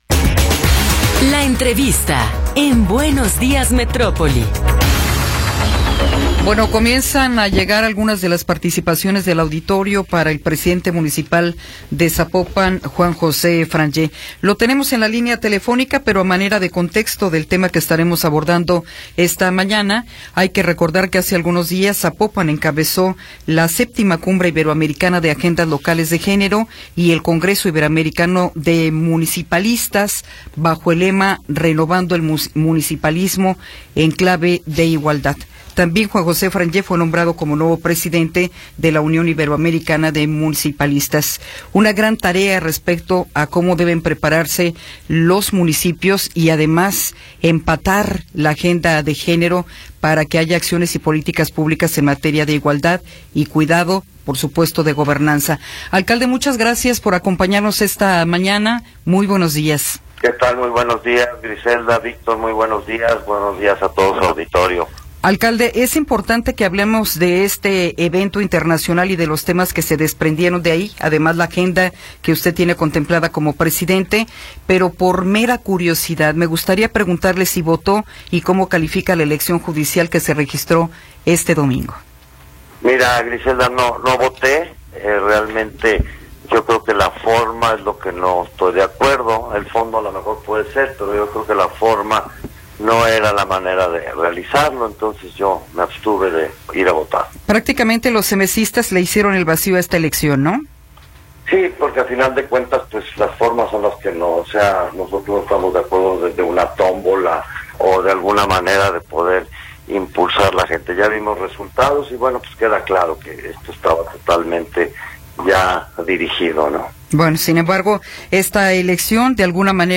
Entrevista con Juan José Frangie